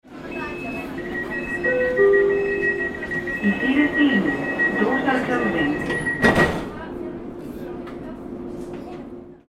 Inside Subway Train Car Ambience Sound Effect
Description: Inside subway train car ambience sound effect. Metro train standing at the station with announcement chime or door-closing bell, doors closing, and official speaker announcement.
Authentic sound recording from a metro train in Greece.
Inside-subway-train-car-ambience-sound-effect.mp3